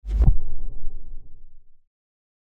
closedoor.mp3